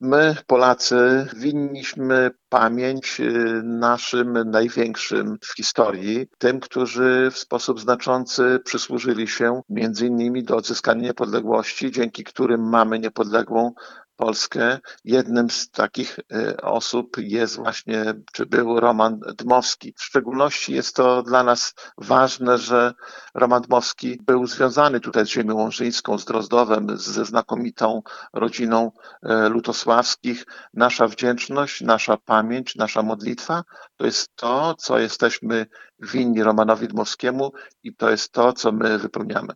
Był to wielki polityki jedne ze współtwórców niepodległej Polski – mówi starosta łomżyński, Lech Szabłowski i dodaje, że pamięć o takich bohaterach jest naszym obowiązkiem.